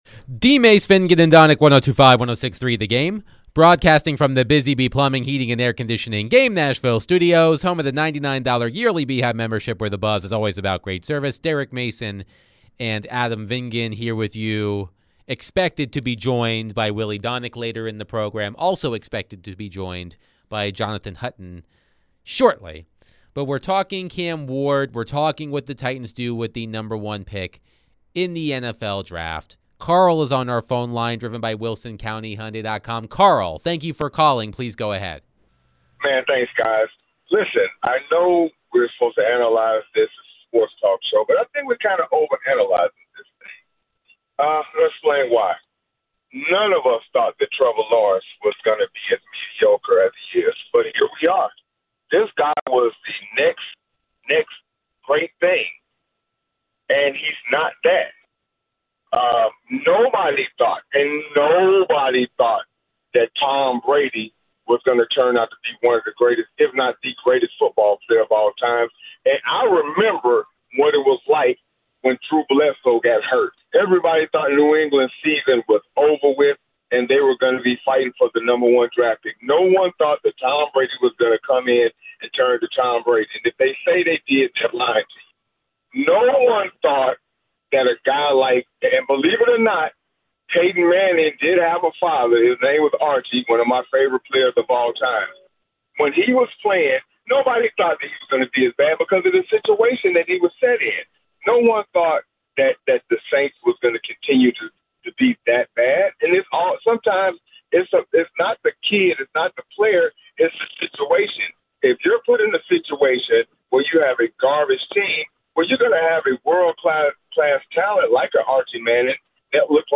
the guys started off answering more calls and texts about the Titans. What’s the status of Will Levis? What will the Titans do with the young QB?